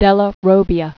(dĕlə rōbē-ə, dĕllä rōbbyä), Luca 1400?-1482.